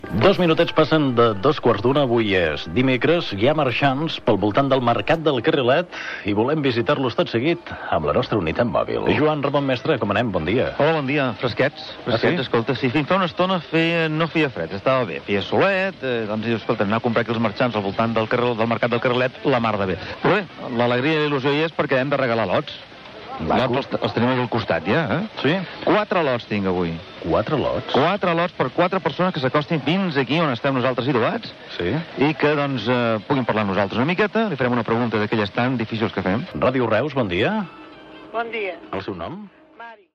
Hora, connexió amb la unitat mòbil al Mercat del Carrilet de Reus per fer un concurs
Entreteniment